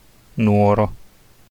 Nuoro (Italian pronunciation: [ˈnuːoro]
It-Nuoro.ogg.mp3